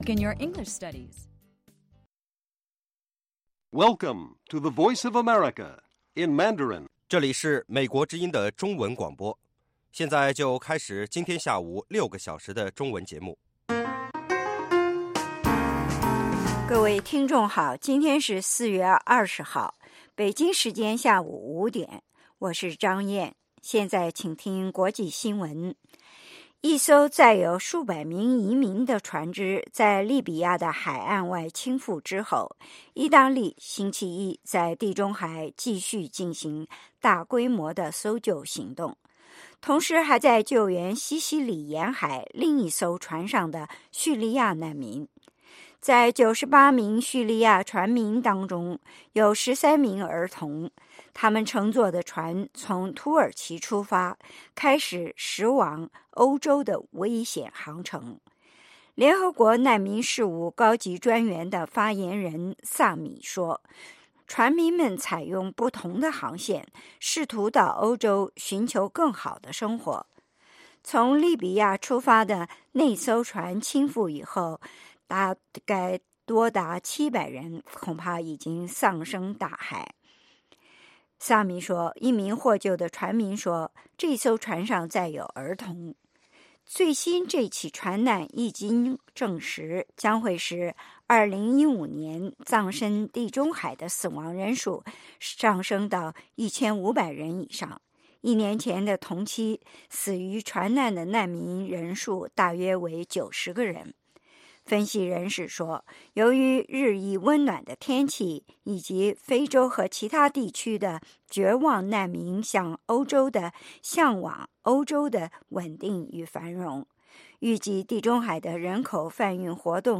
北京时间下午5-6点广播节目。 内容包括国际新闻和美语训练班（学个词， 美国习惯用语，美语怎么说，英语三级跳， 礼节美语以及体育美语）